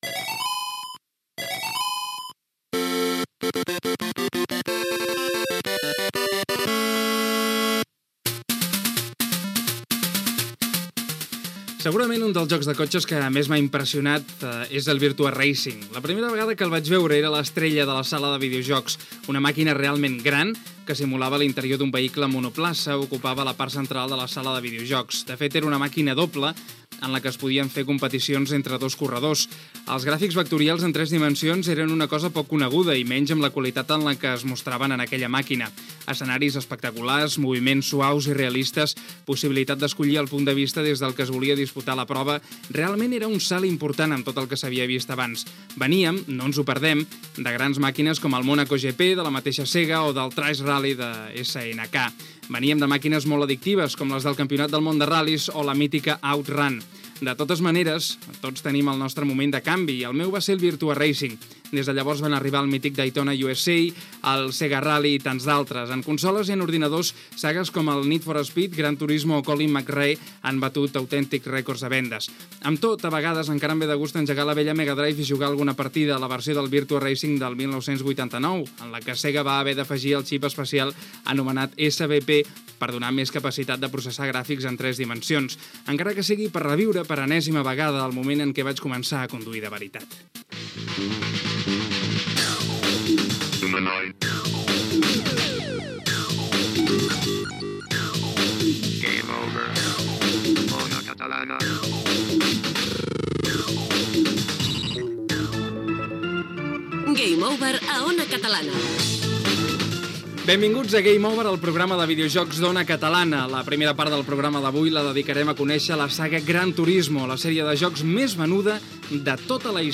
careta del programa, sumari de continguts, reportatge sobre "Gran Turismo"